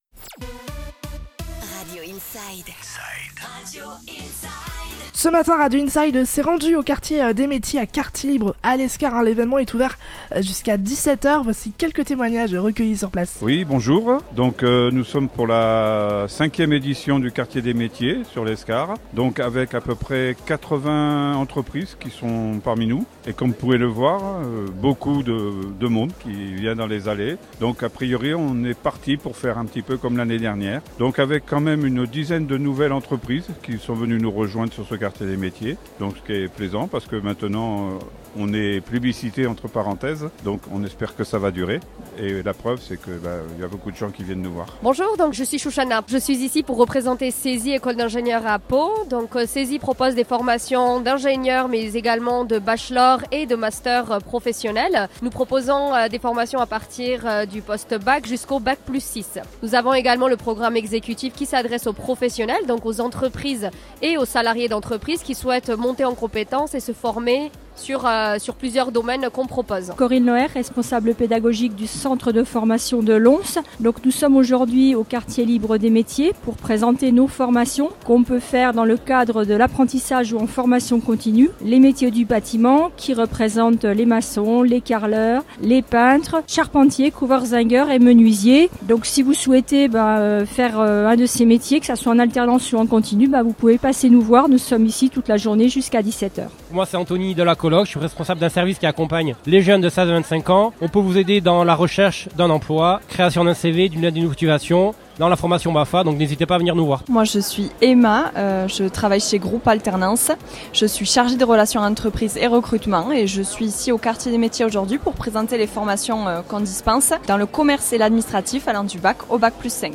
Radio Inside était sur place ce matin au Quartier des Métiers à Lescar pour reccueillir plusieurs témoignages d'entreprises à la recherche de nouveaux talents, ainsi que des organismes, des centres de formations offrant un accompagnement professionnnel.